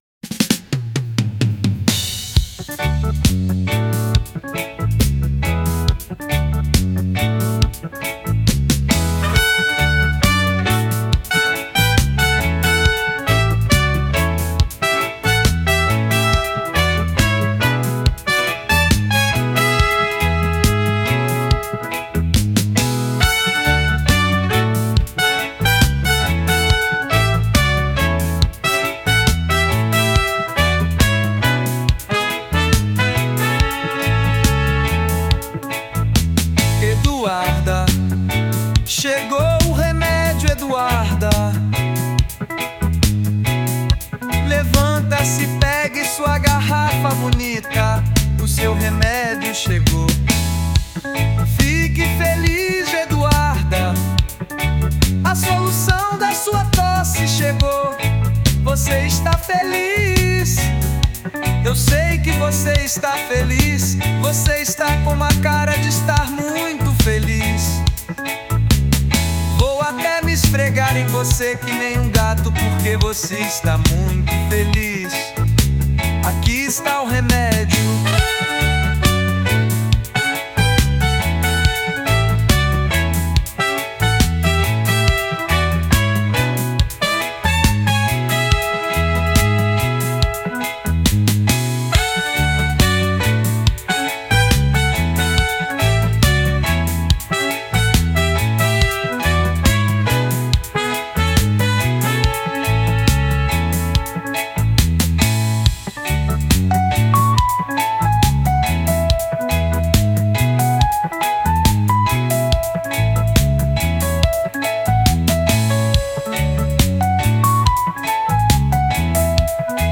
Versão Reggae 1